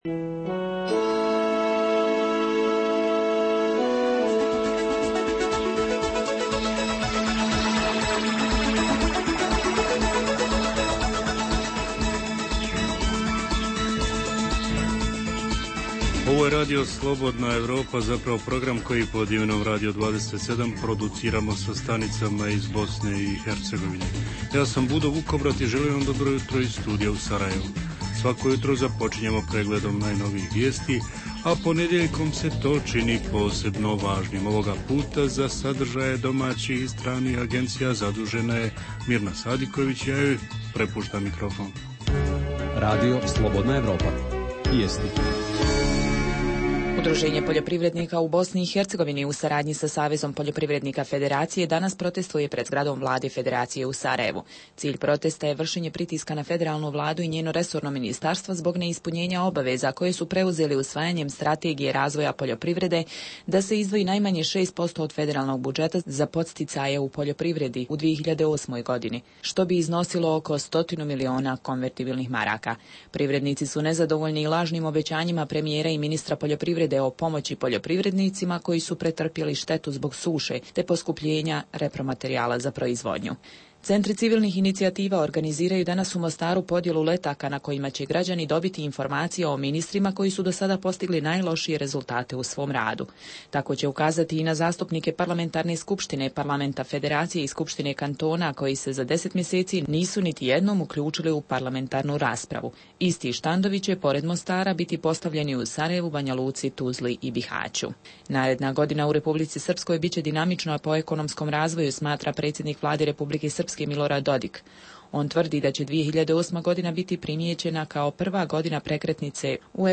Osim događaja dana koje javljaju reporteri svih članica Radija 27 i najaktuelnijih tema minulog vikenda, emitujemo redovnu rubriku Raseljeničko-povratnička mini-hronika. Redovni sadržaji jutarnjeg programa za BiH su i vijesti i muzika.